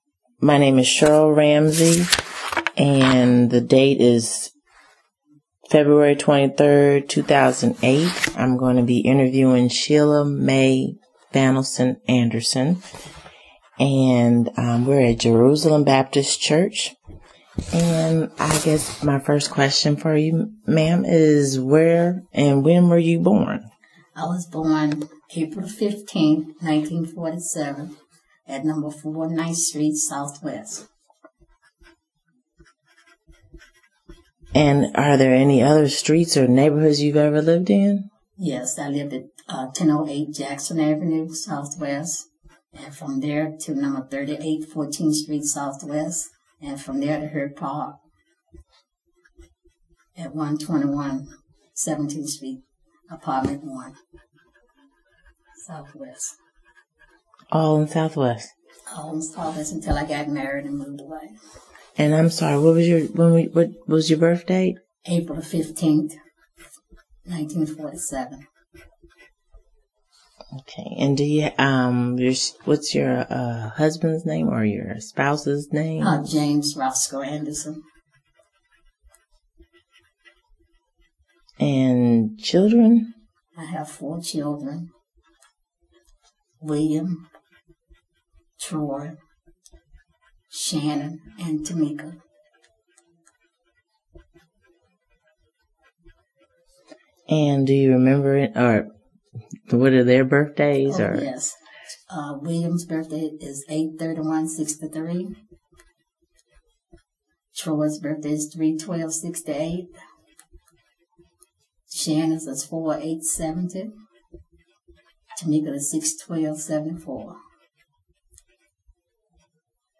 Location: Jerusalem Baptist Church